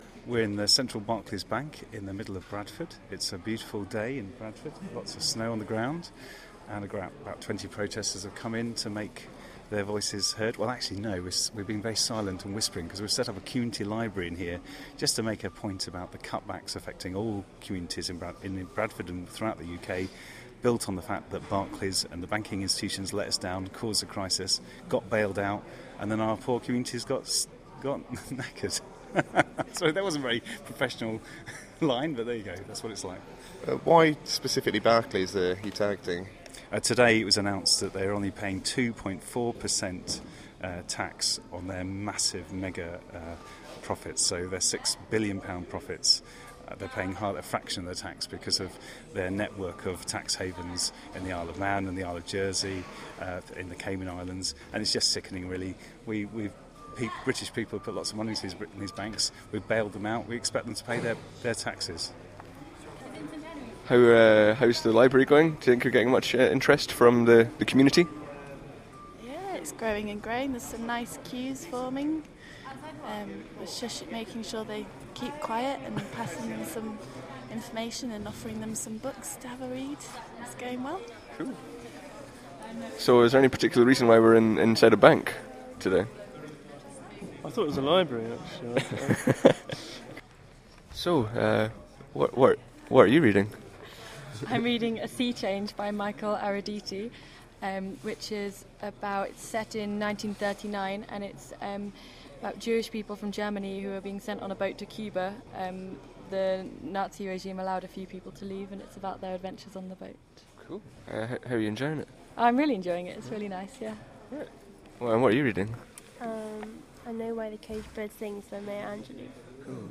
Sound Interviews from the occupation
barclays_read_in.mp3